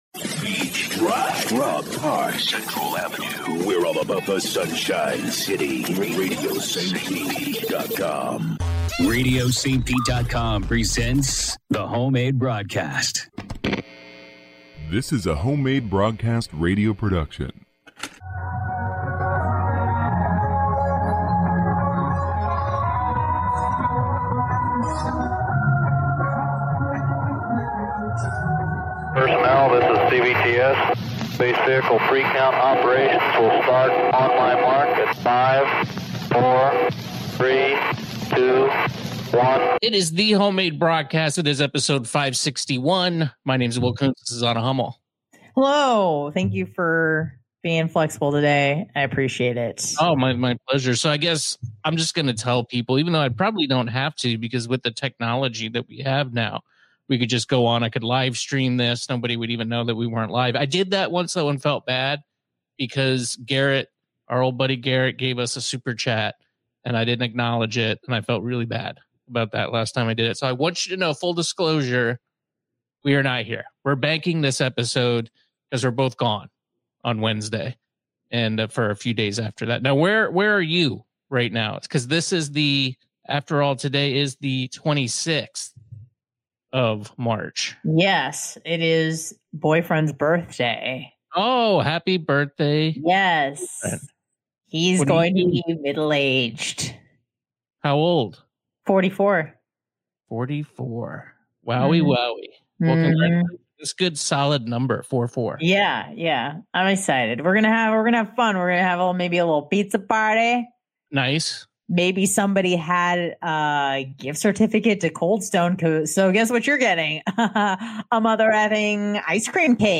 Full disclosure, this is a pre-recorded ep. but then again, aren't all podcasts pre-recorded episodes? We were both out of town this week so we talked about a new proposed Florida law that could forever change how we tip and discuss if tipping has gotten out of control.